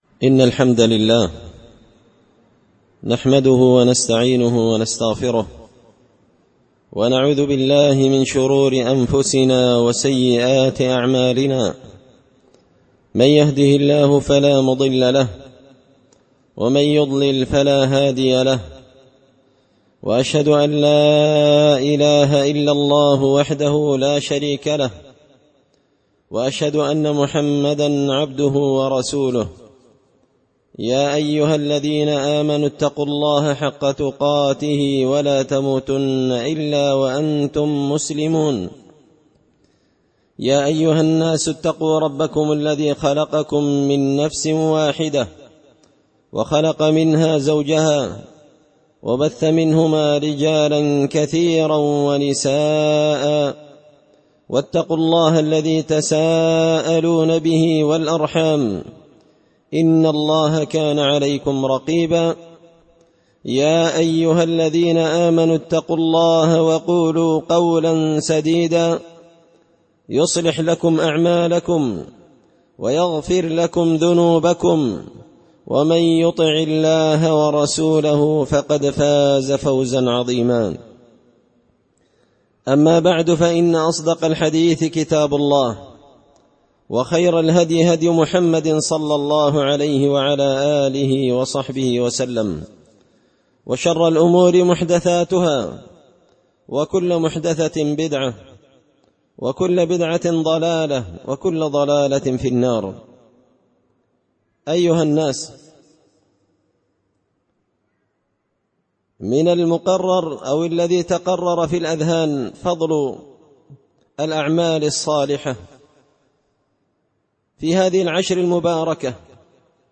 خطبة جمعة بعنوان – من الأعمال الصالحة
دار الحديث بمسجد الفرقان ـ قشن ـ المهرة ـ اليمن